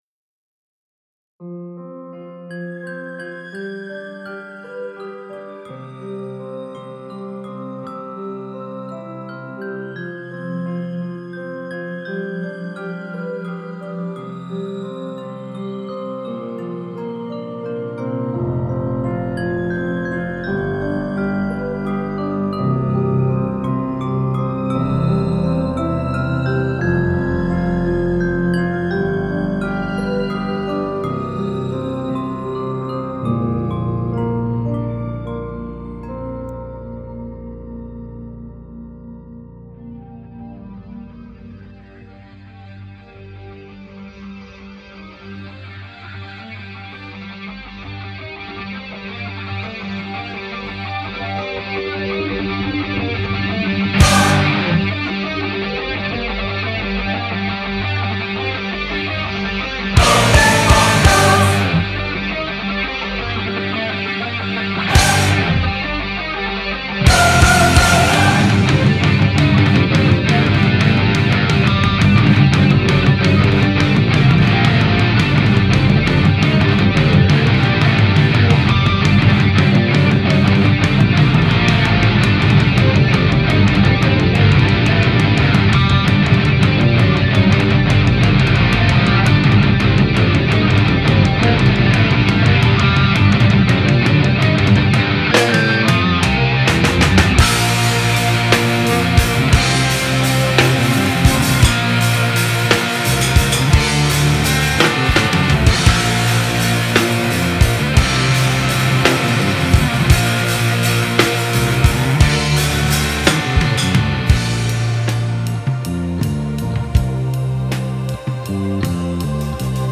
rock progressive metal